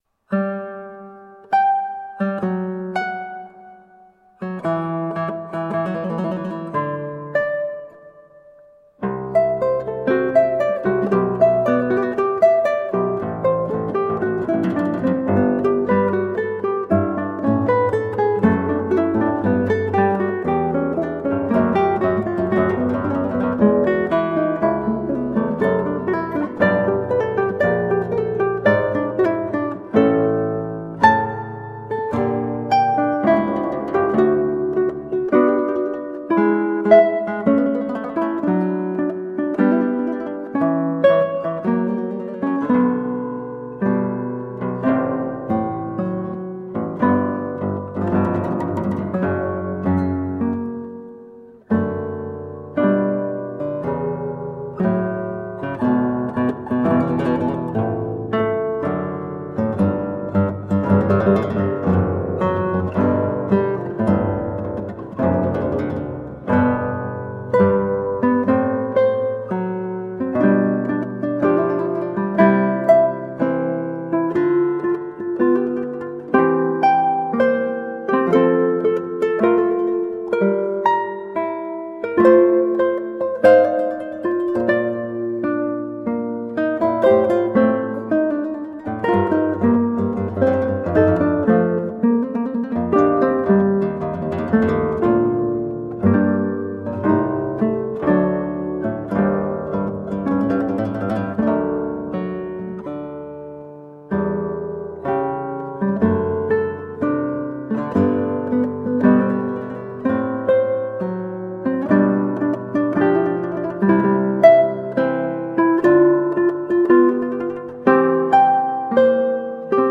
Colorful classical guitar.
Classical Guitar